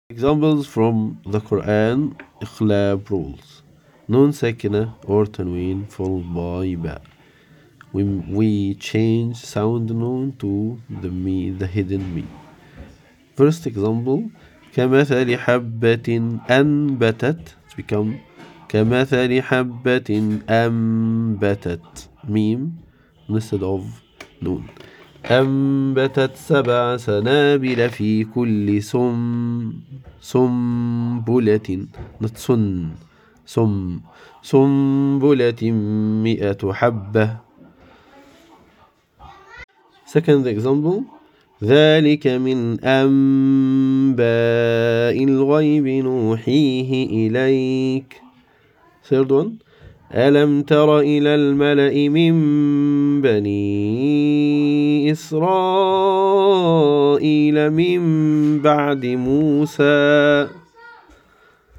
Examples from the Qur’an:
Examples-of-iqlab-rule-from-the-quran.mp3